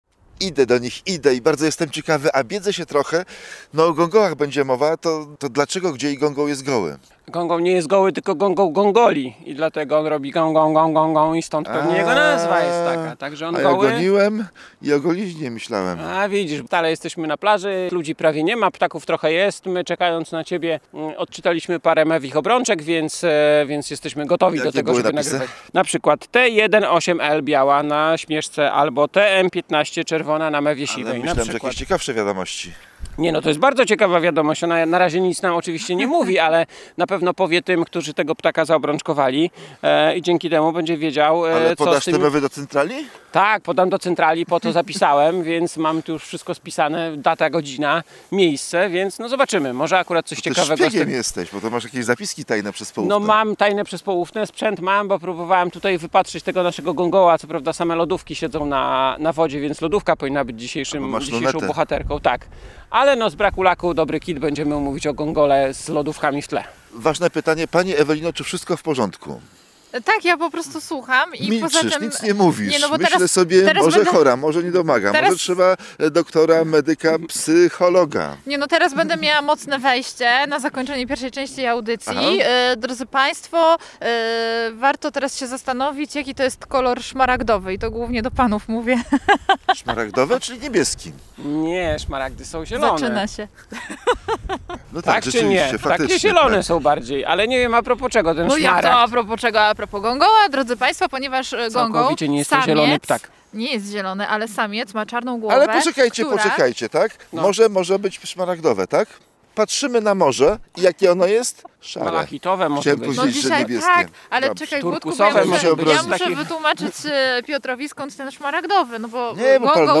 - Czy gągoły bywają gołe? - pyta dziennikarz.